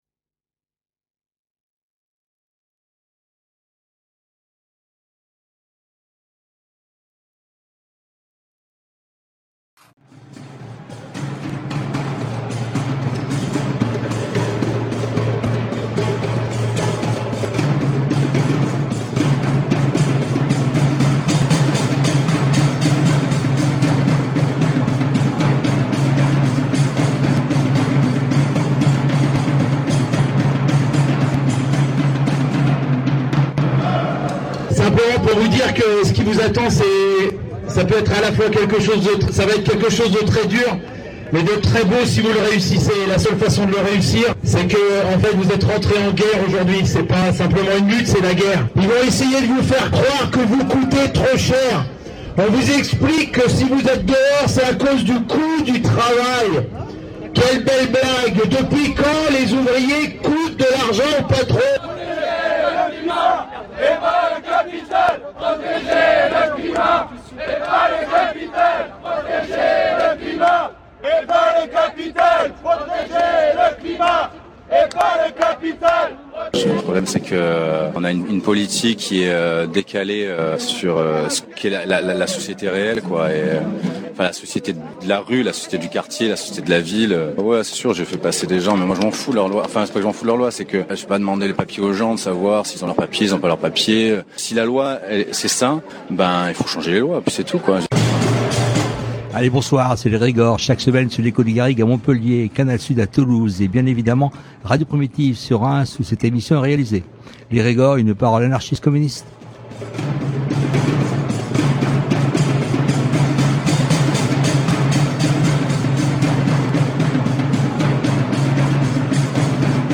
Seconde partie de notre entretien avec un membre de La Horde.